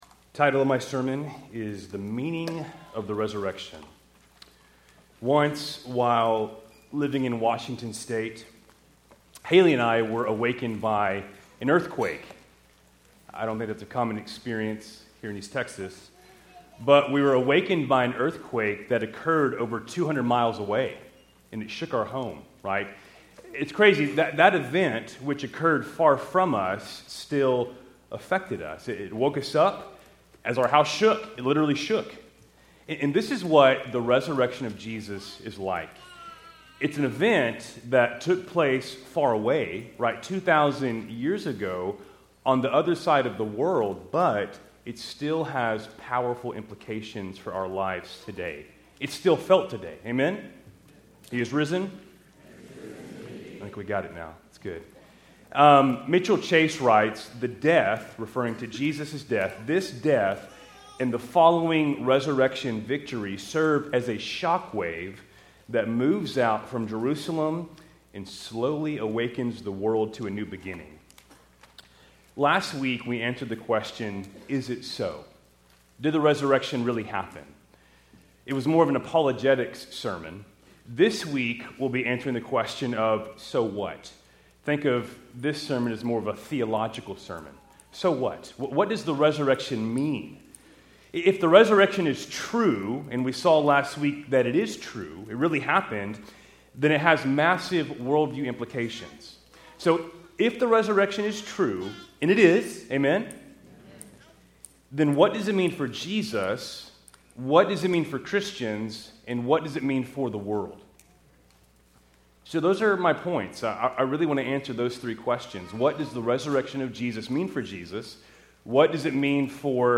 Keltys Easter Worship Service, April 20, 2025